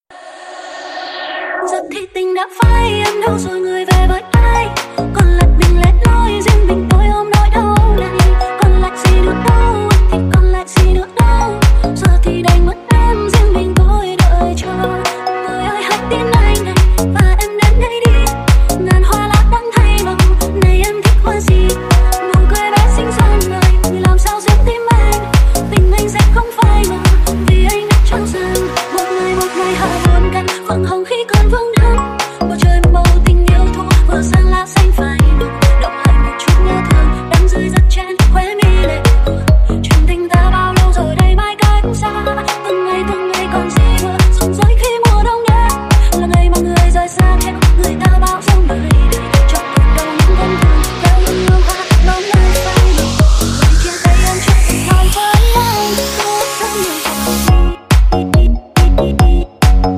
Nhạc Chuông TikTok